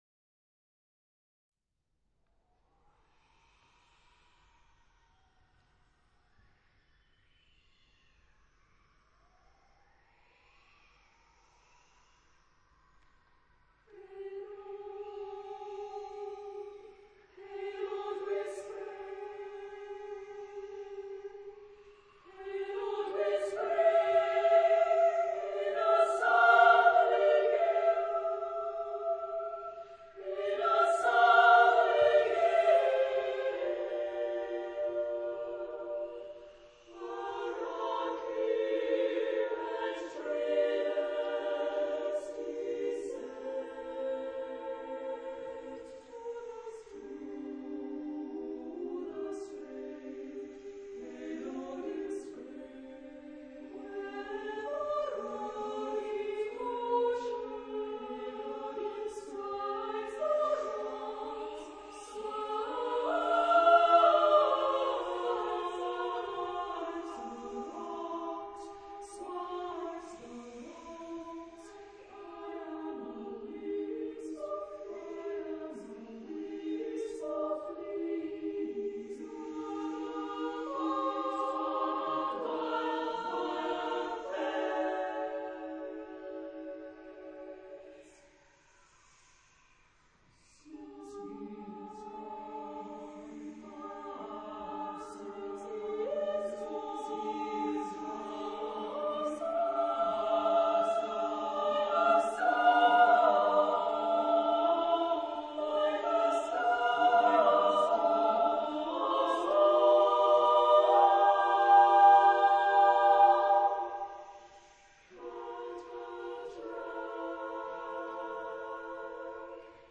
Mood of the piece: sorrowful
Type of Choir: SSAATB  (6 mixed voices )